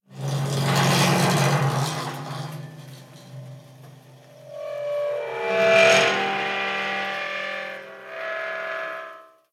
Bisagras de casa de suspense
suspense
puerta
bisagra
chirrido
Sonidos: Hogar